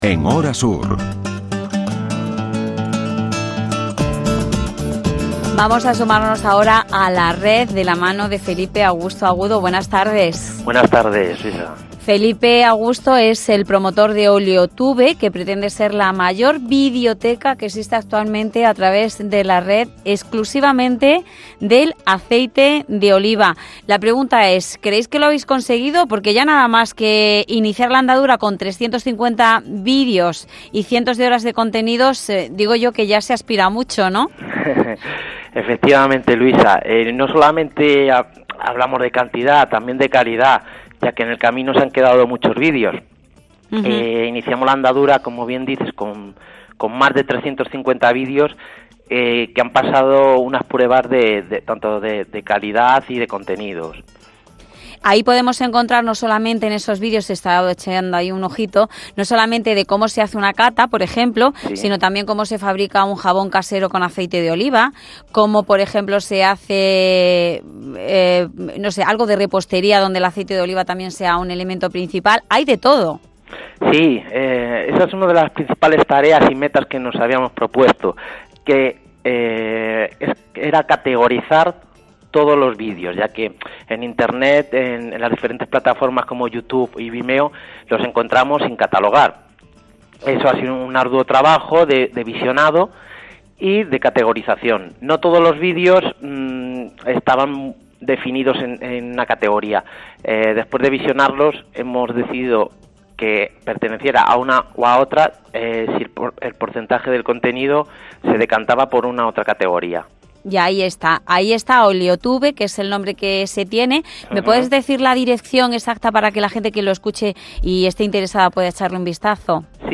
Entrevista para el programa de radio Jaén en Hora Sur con motivo de la presentación del canal de videos sobre el mundo del aceite de oliva «Oleotube»